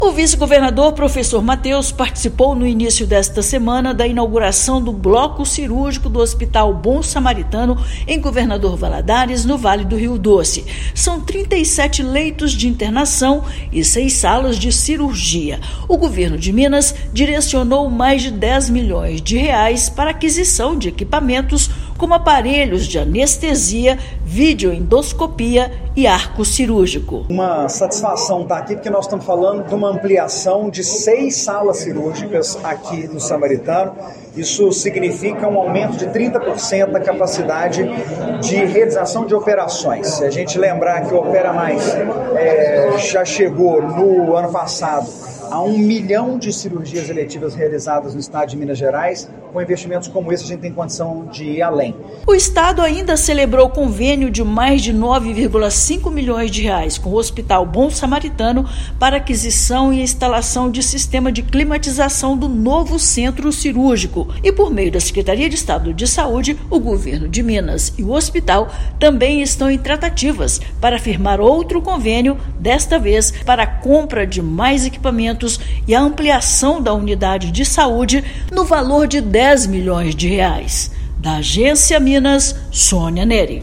Estado direcionou mais de R$ 10 milhões para aquisição de equipamentos; Bom Samaritano passa a ser unidade hospitalar de destaque local para cirurgias de pequeno e médio porte. Ouça matéria de rádio.